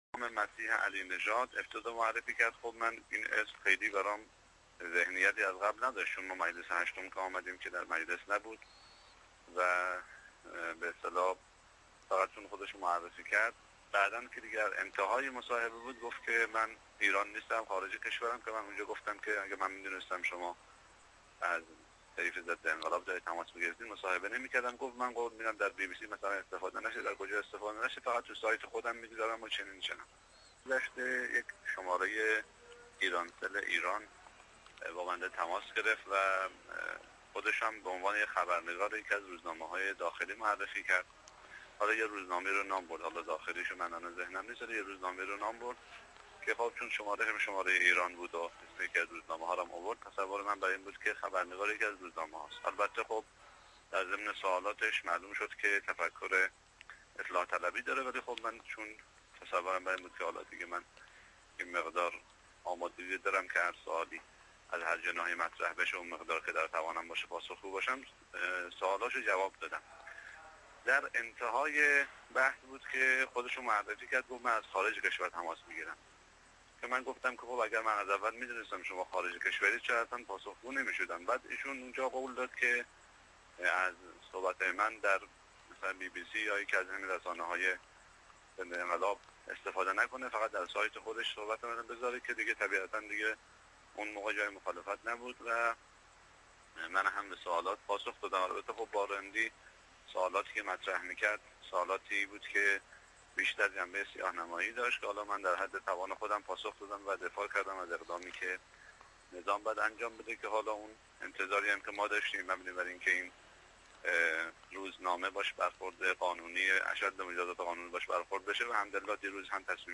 فایل صوتی مصاحبه بولتن نیوز با طاهری و اعلام انزجار وی از مصاحبه با این ضد انقلاب در ادامه می آید.